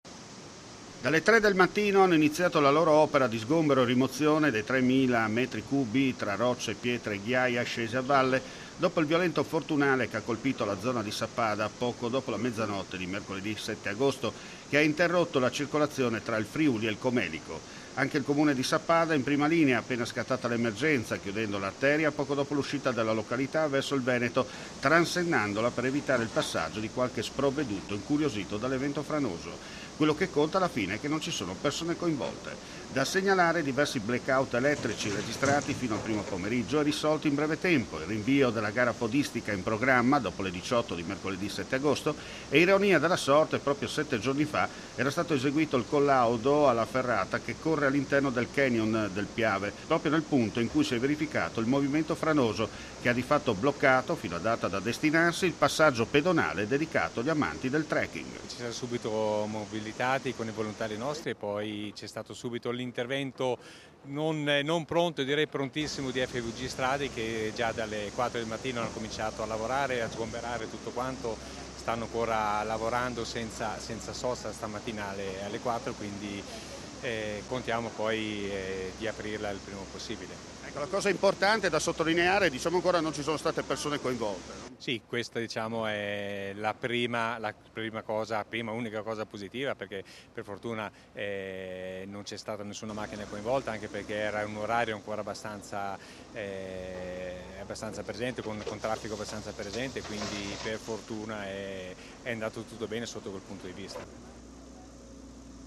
INTERVISTA A SILVIO FAUNER ASSESSORE COMUNALE SAPPADA